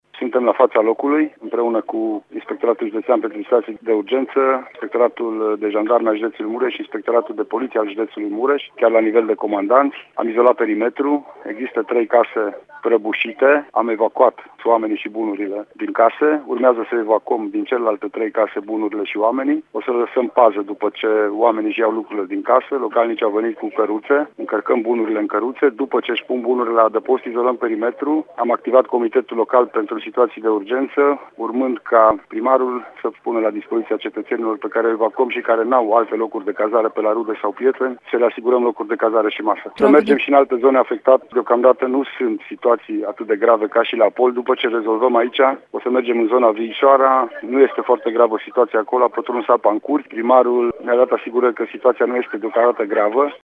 Acolo sunt doar câteva curți inundate, a declarat Lucian Goga: